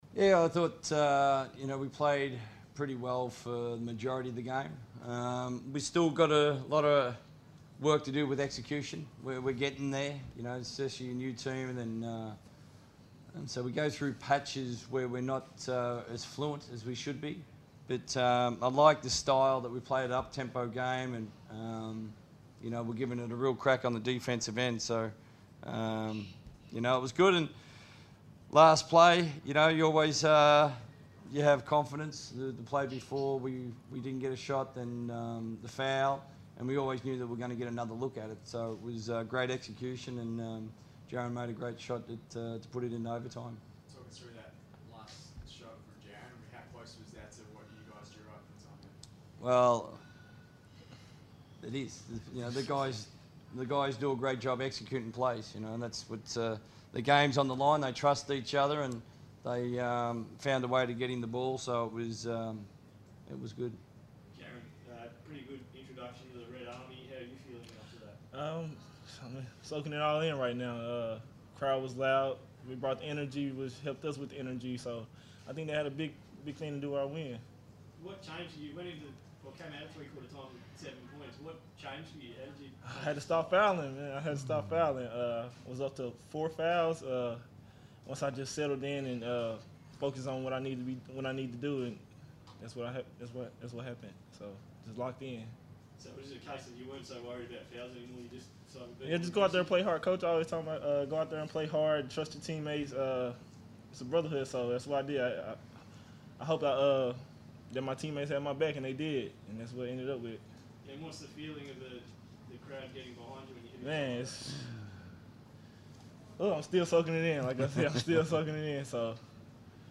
speak to the media following the Perth Wildcats thrilling win over the Cairns Taipans.